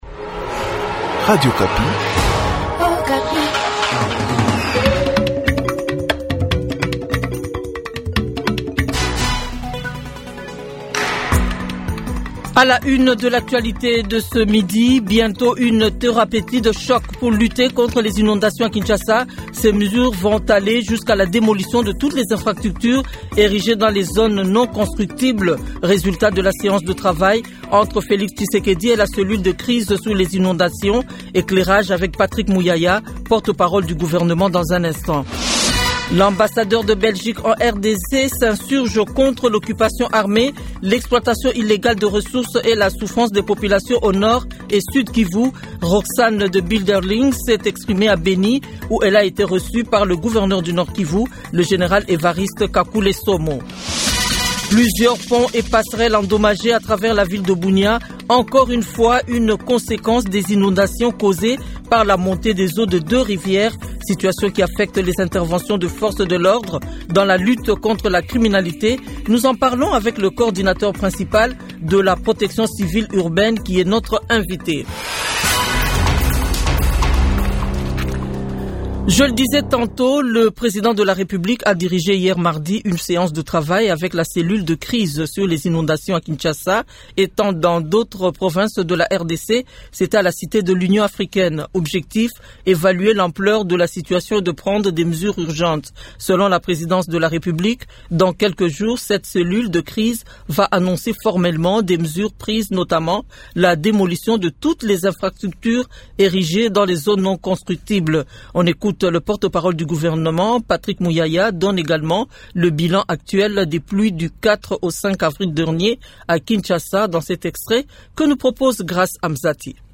Journal Midi
Journal 12h mercredi 16 avril 2025